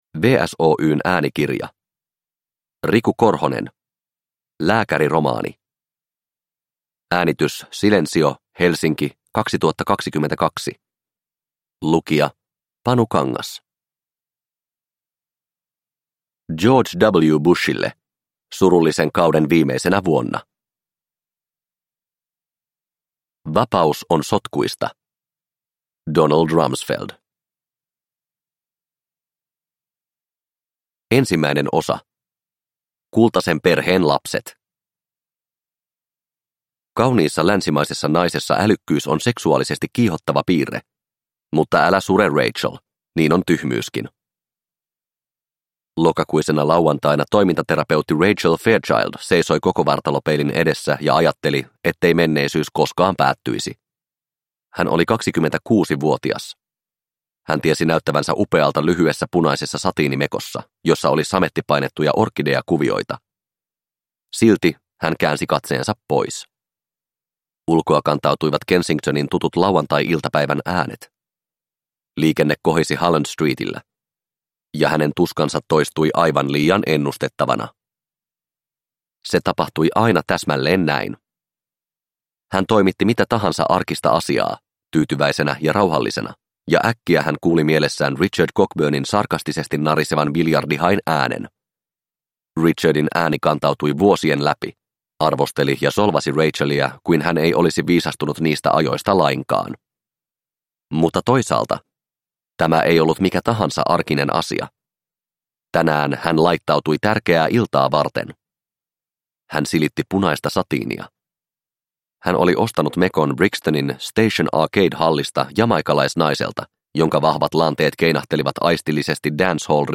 Lääkäriromaani – Ljudbok – Laddas ner